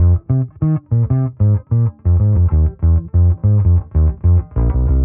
Index of /musicradar/dusty-funk-samples/Bass/95bpm
DF_PegBass_95-F.wav